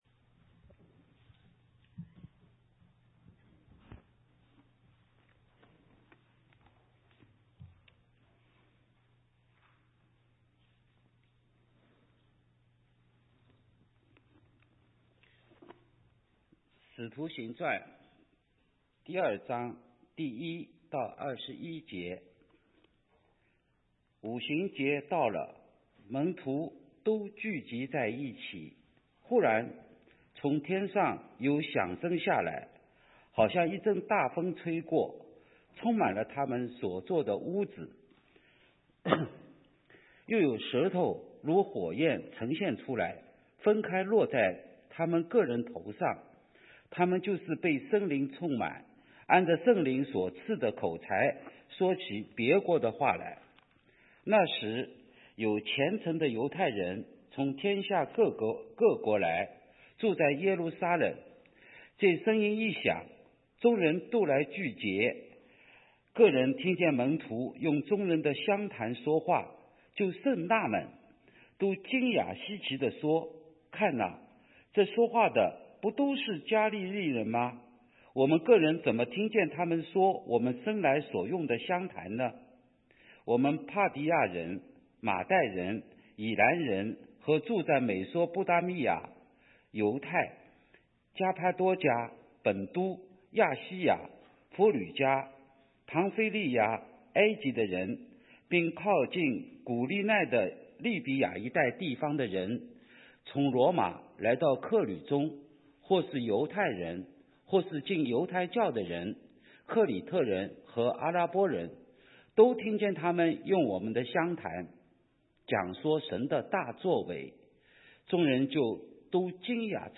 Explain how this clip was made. Service Type: 10:30 AM Service